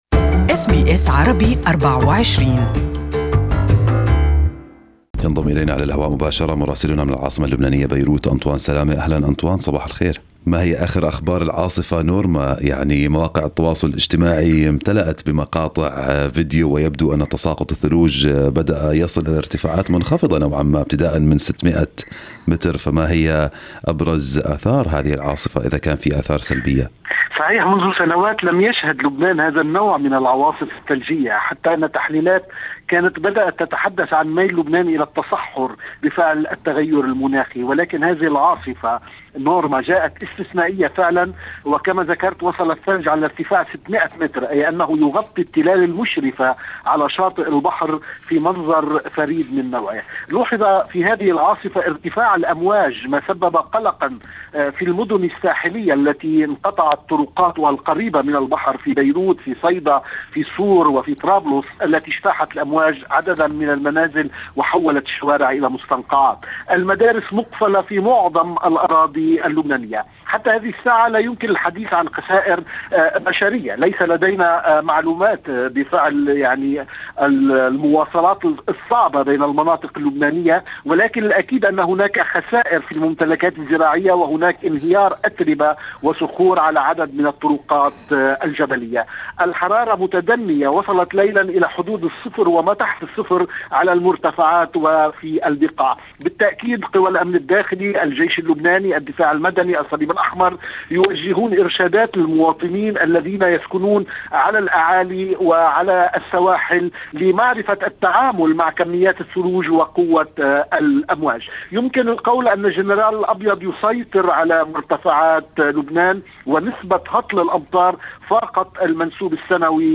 Our correspondent in Beirut has the details